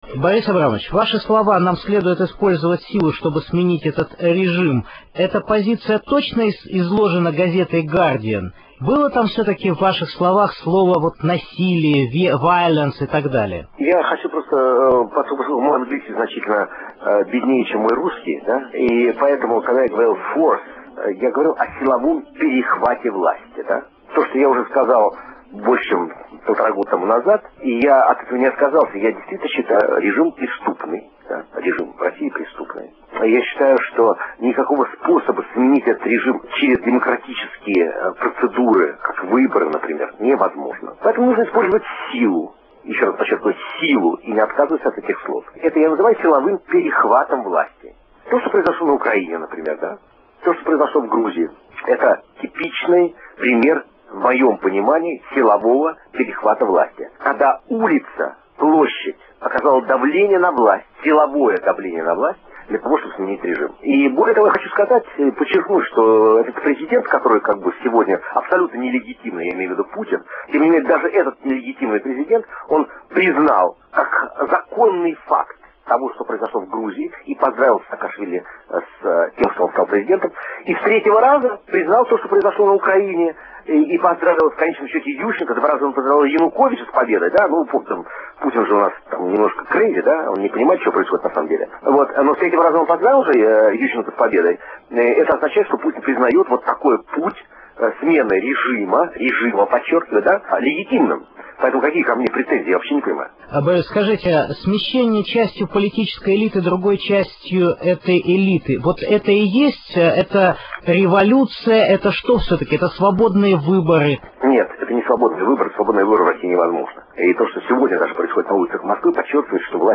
Березовский уточнил свою позицию в интервью Радио Свобода